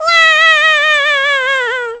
Pikmin Falling Sound Buttons
Pikmin falling
Download Pikmin falling Sound effect Button free on sound buttons.